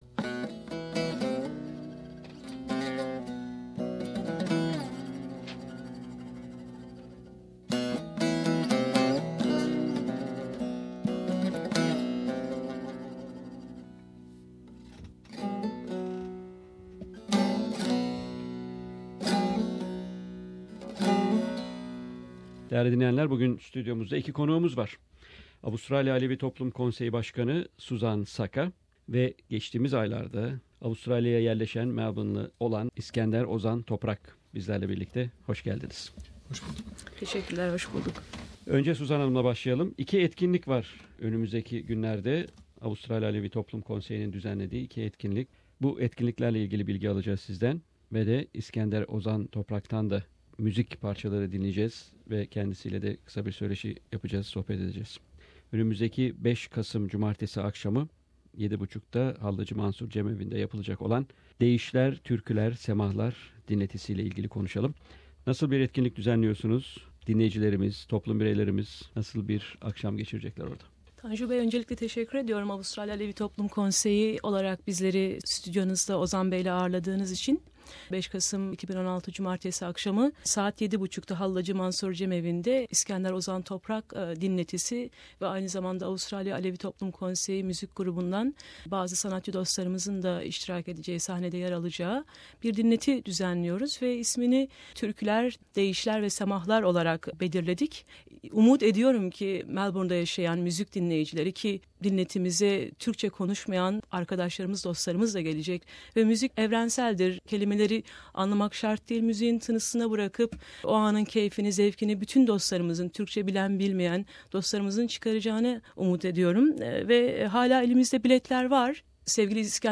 at SBS studios